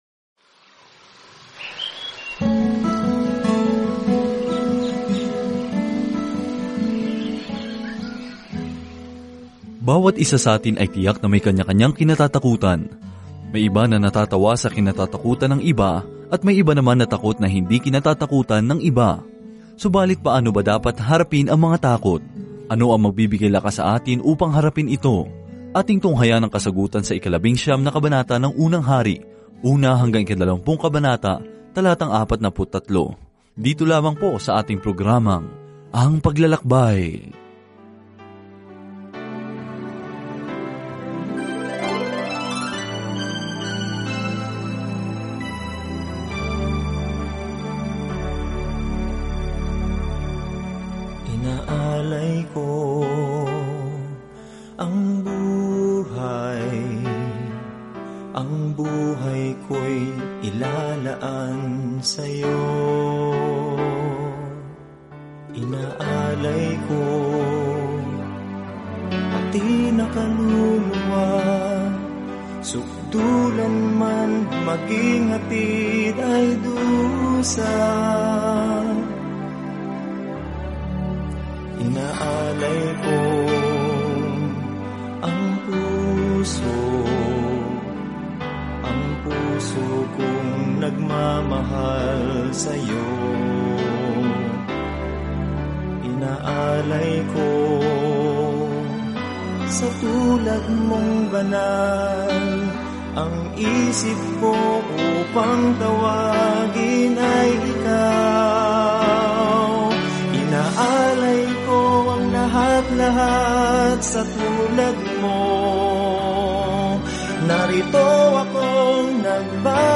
Banal na Kasulatan 1 Mga Hari 19 1 Mga Hari 20 Araw 11 Umpisahan ang Gabay na Ito Araw 13 Tungkol sa Gabay na ito Ang aklat ng Mga Hari ay nagpatuloy sa kuwento kung paano umunlad ang kaharian ng Israel sa ilalim nina David at Solomon, ngunit kalaunan ay nagkalat. Araw-araw na paglalakbay sa 1 Mga Hari habang nakikinig ka sa audio study at nagbabasa ng mga piling talata mula sa salita ng Diyos.